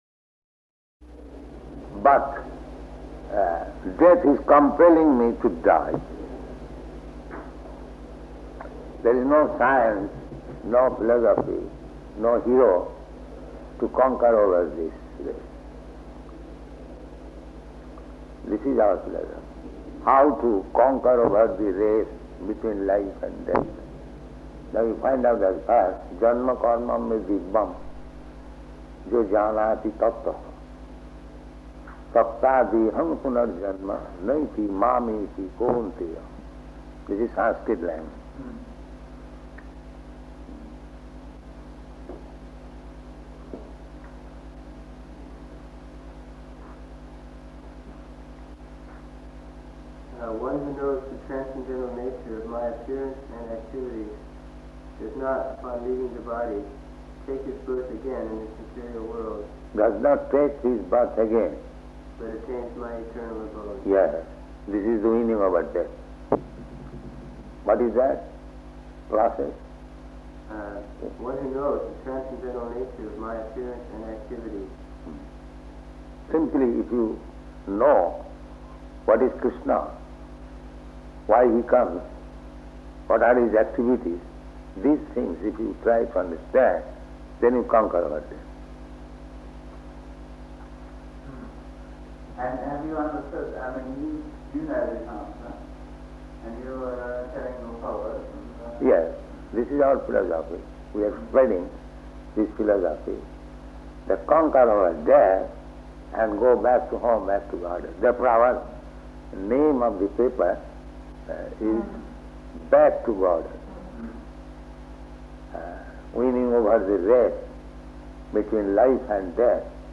Room Conversation with Graham Hill --:-- --:-- Type: Conversation Dated: July 26th 1973 Location: London Audio file: 730726R1.LON.mp3 Prabhupāda: But death is compelling me to die.